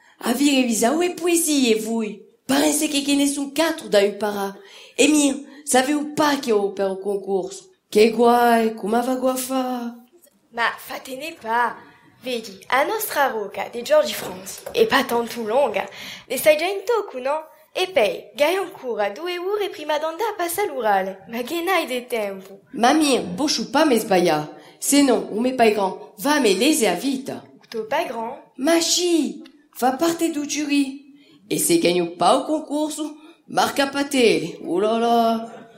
15 January 2019 at 1:16 am Sounds like it’s from a film or TV show.
17 January 2019 at 8:37 am Agreed that it has a lot of French-like phonology.